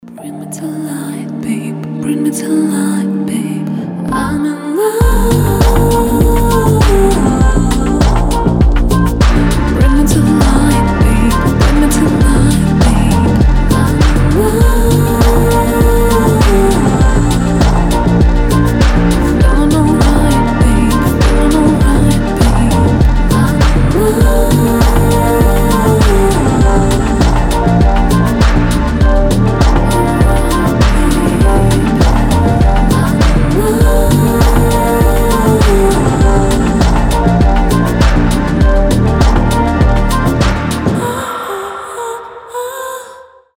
• Качество: 320, Stereo
мелодичные
Electronic
чувственные